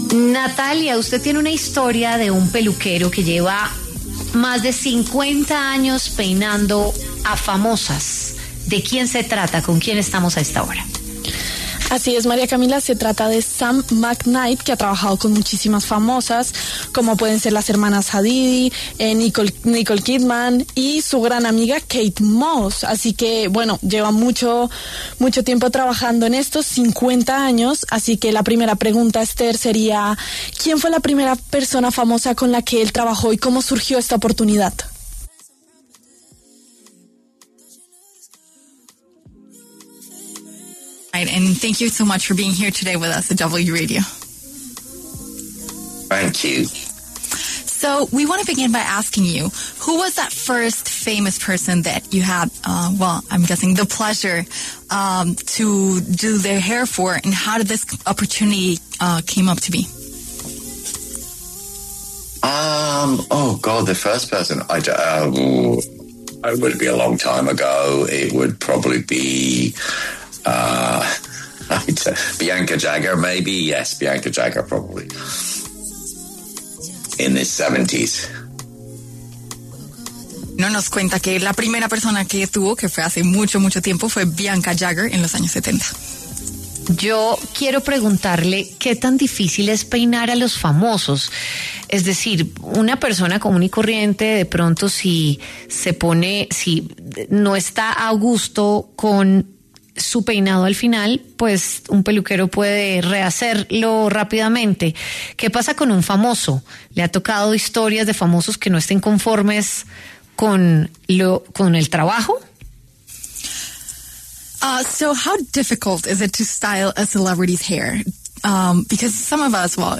Según comentó en entrevista con La W, la primera famosa que peinó fue Bianca Jagger.